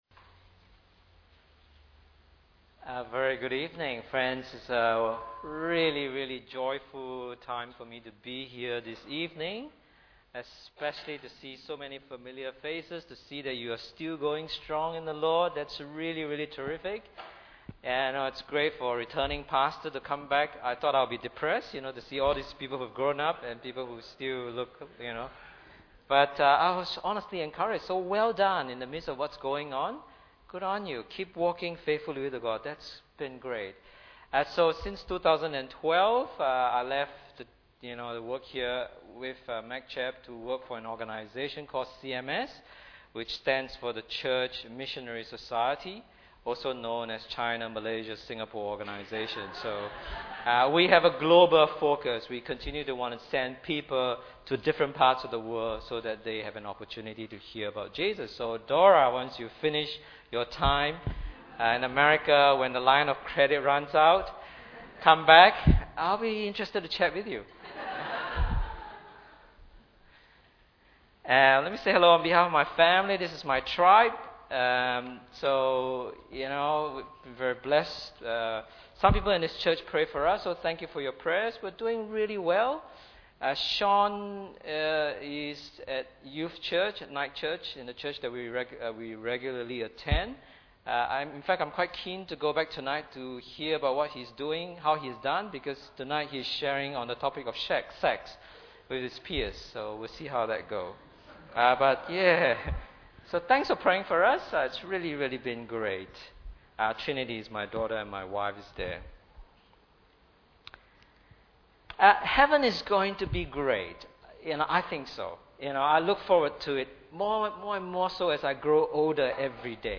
Bible Text: Philippians 1:27 – 2:11 | Preacher